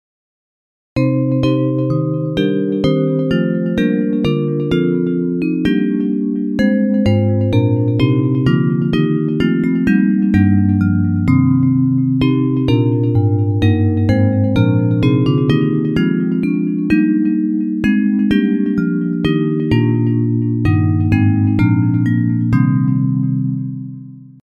Bells Version
Music by: English melody;